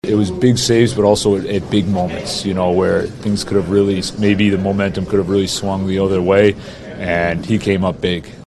Arturs Silovs stopped 30 of 32 shots by the Oilers to improve to 9-6-2 on the season.  Muse says Silovs came up big when the game could have swung in Edmonton’s direction.